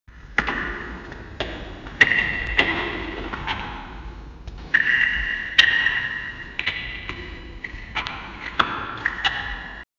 walking-in-an-abandoned-b-gix7hdy2.wav